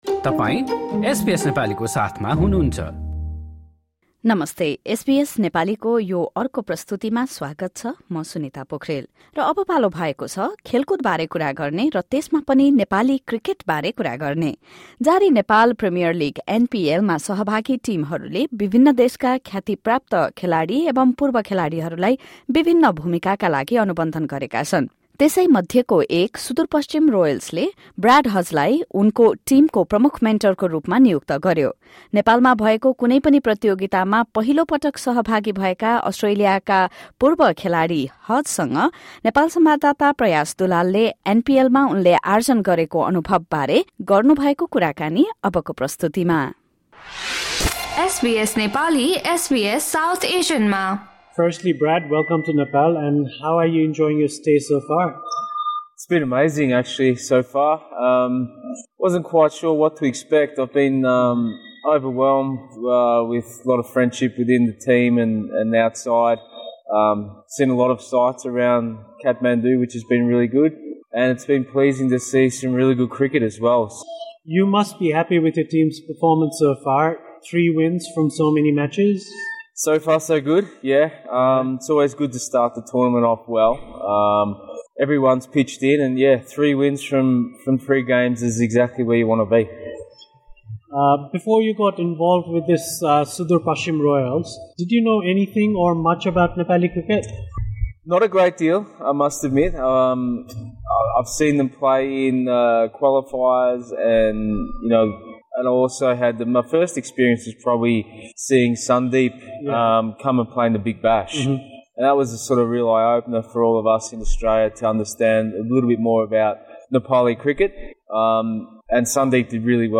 Former Australian cricketer Brad Hodge, the chief mentor of Sudurpaschim Royals in the ongoing Nepal Premier League (NPL), has shared his experiences of staying in Nepal. Hodge spoke to SBS Nepali about his first-ever Nepali tournament experience, the future of Nepali cricket, and even his culinary adventures.